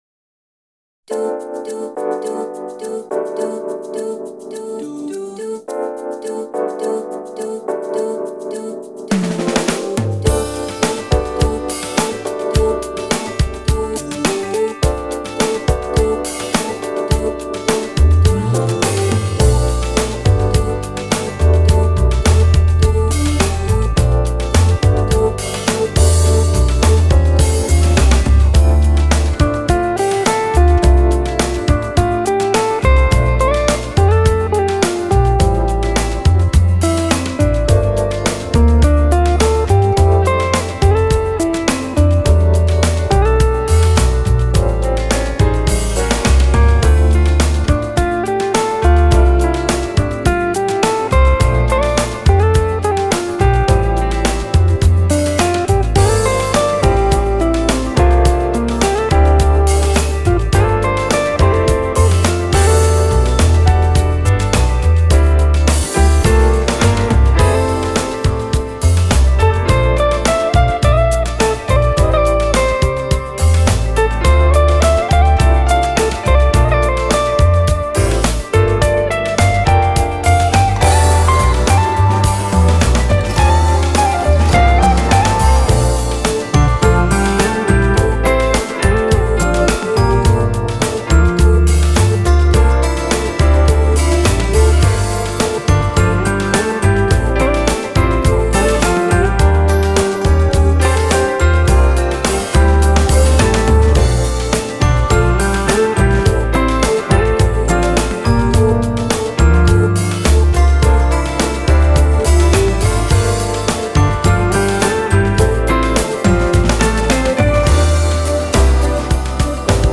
Drums
Vocals
가을에 어울리는 상큼한 곡이네요^^